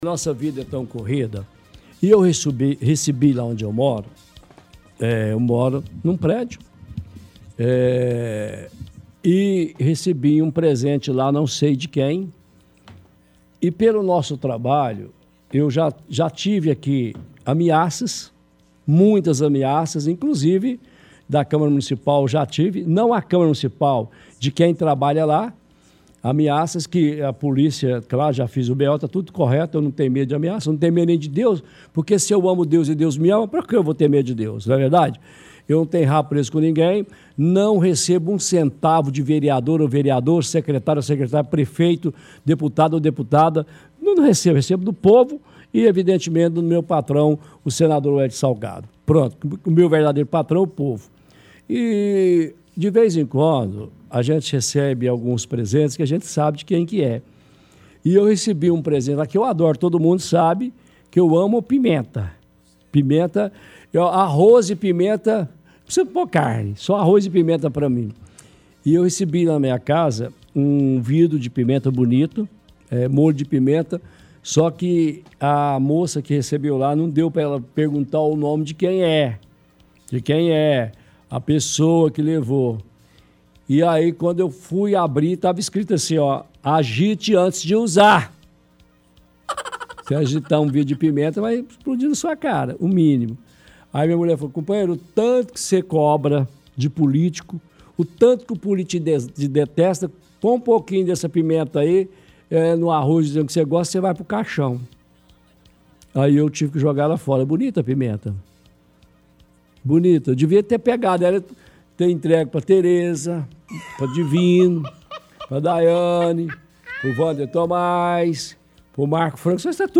– Coloca áudios antigos do prefeito